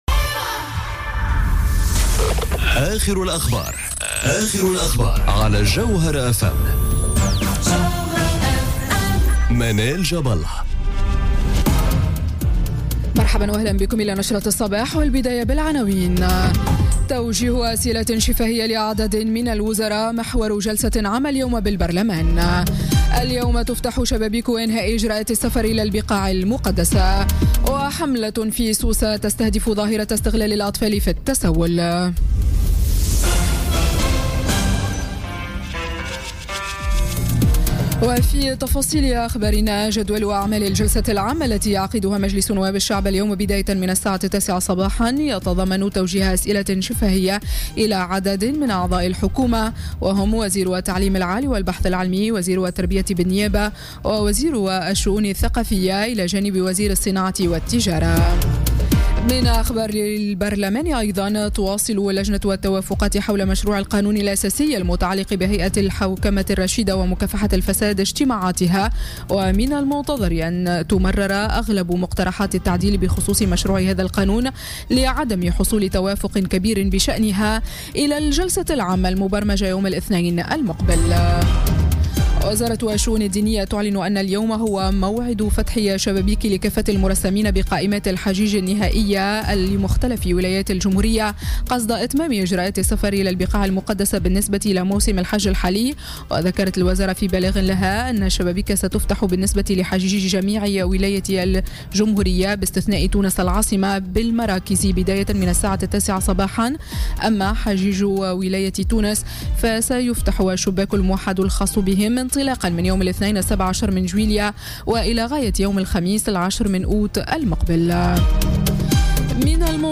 نشرة أخبار السابعة صباحا ليوم السبت 08 جويلية 2017